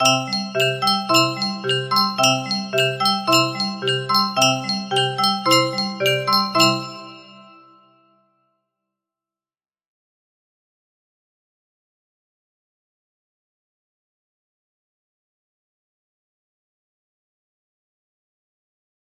Jingle Bells -Me music box melody